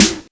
Index of /90_sSampleCDs/USB Soundscan vol.02 - Underground Hip Hop [AKAI] 1CD/Partition C/06-89MPC3KIT